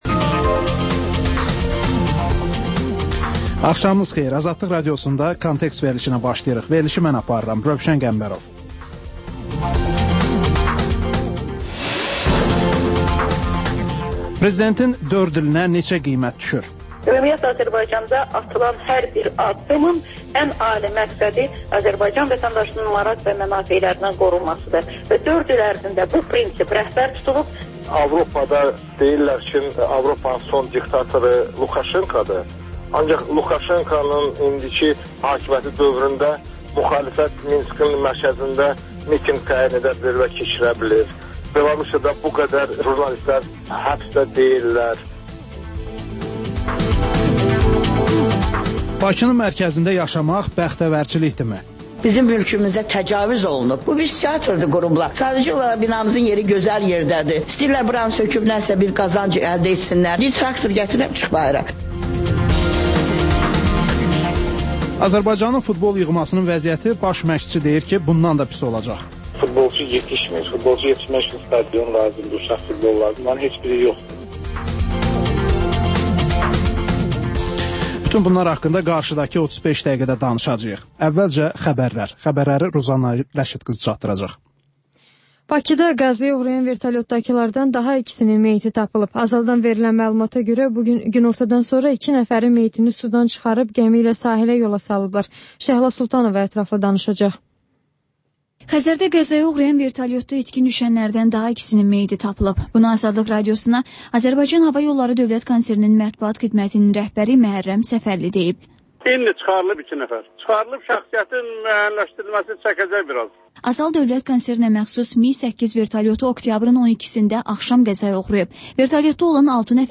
Xəbərlər, müsahibələr, hadisələrin müzakirəsi, təhlillər, sonda isə XÜSUSİ REPORTAJ rubrikası: Ölkənin ictimai-siyasi həyatına dair müxbir araşdırmaları